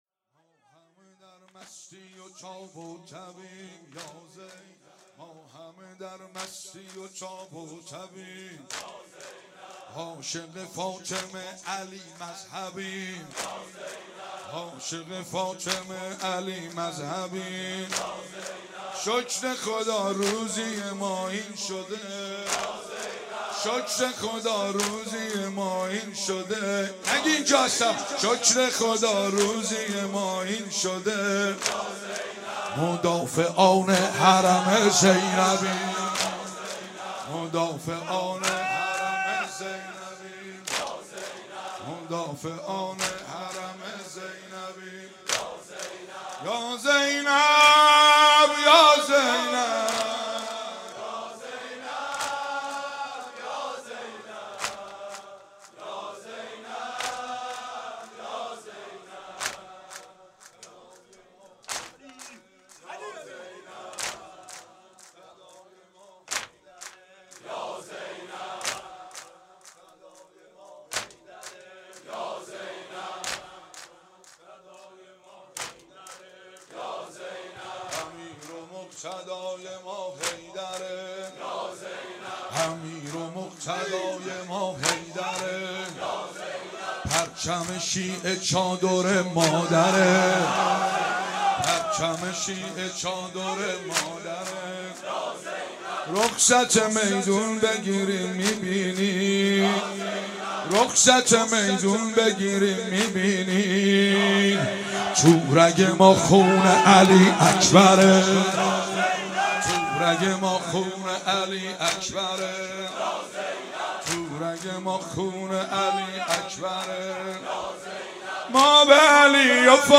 28 تیر 96 - هیئت فاطمیون - واحد - ایشالا از سوریه تا کربلا